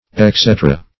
Exedra \Ex"e*dra\ ([e^]ks"[-e]*dr[.a]), n.; pl. Exedr[ae]